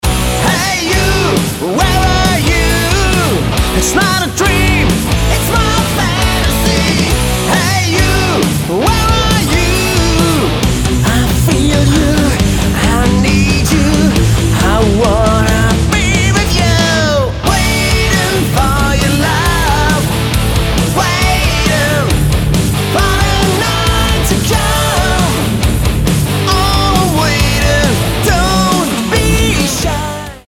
Lead Vocals
Bass, Vocals
Gitarre, Vocals
Drums, Vocals